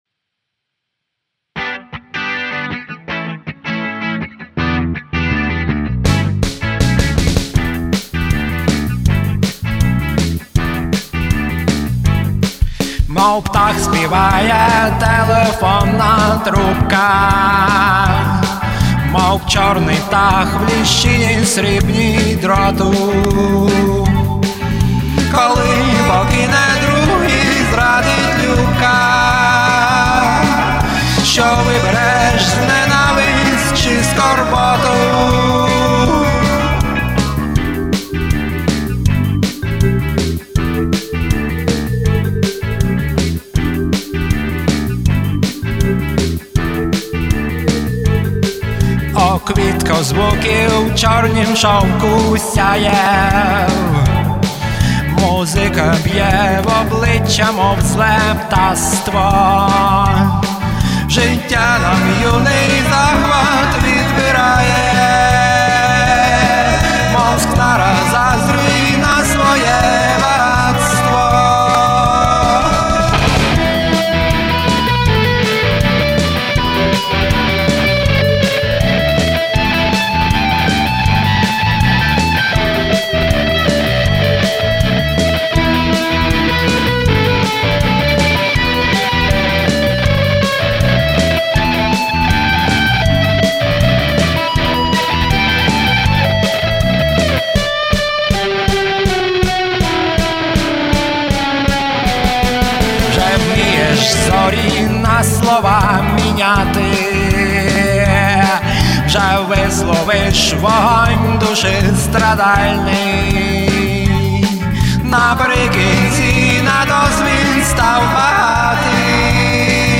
рок-гурт